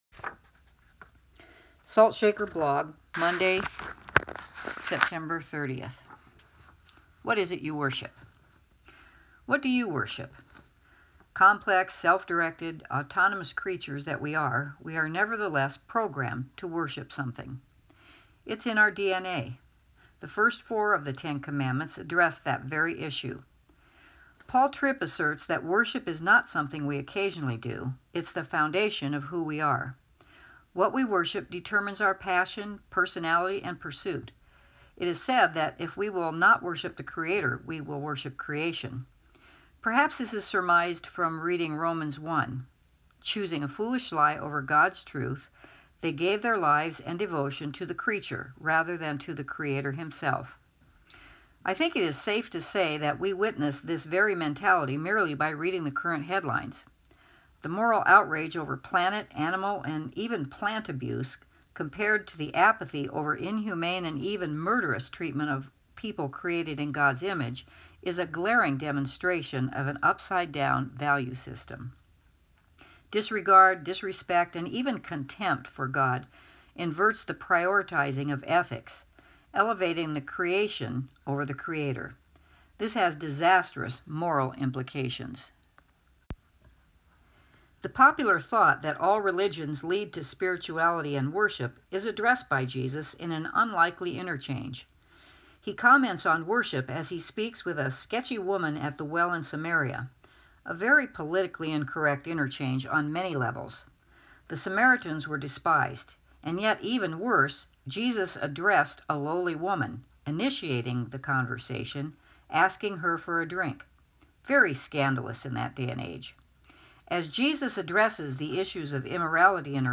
For the audio version read by the author click here.